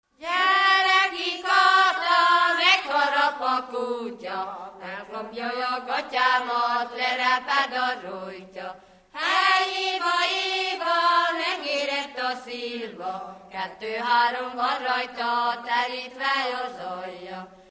Alföld - Bács-Bodrog vm. - Dávod
Előadó: Asszonyok, ének
Stílus: 6. Duda-kanász mulattató stílus